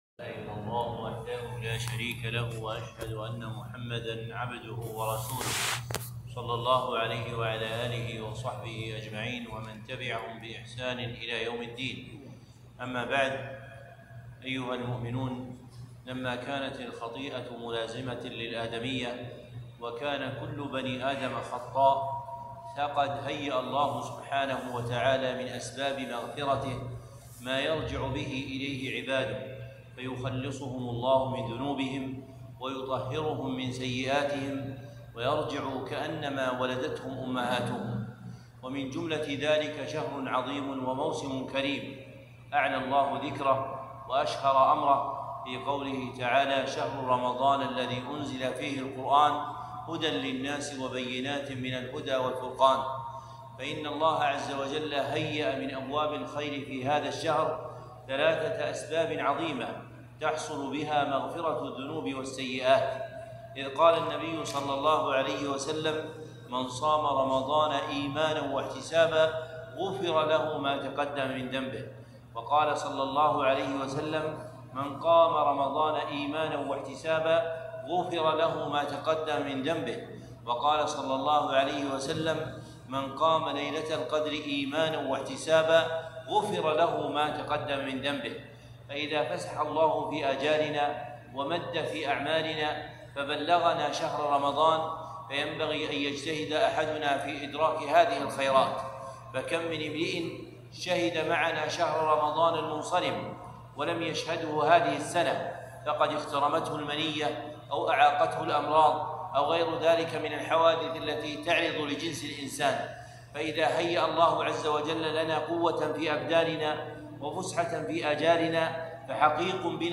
كلمة - غنائم رمضان 1443